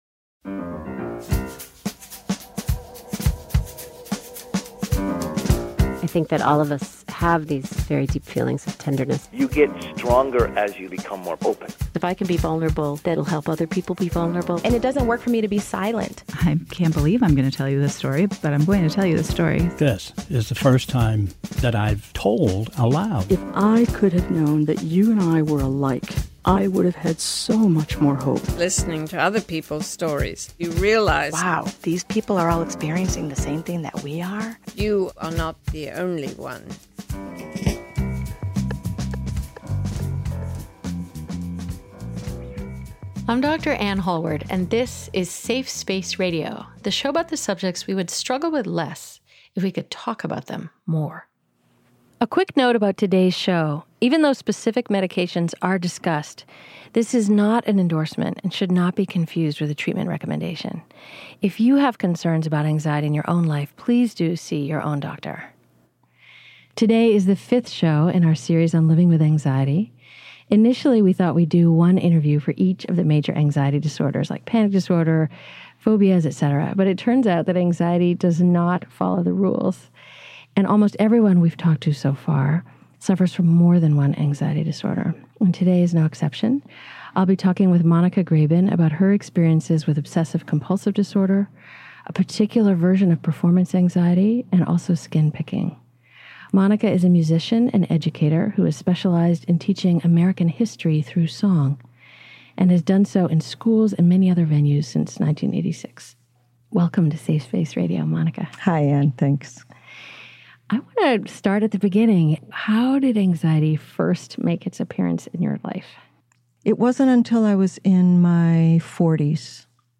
We are a nationally broadcast public radio show dedicated to fostering empathy, reducing stigma and inspiring the courage to have difficult conversations that improve our health.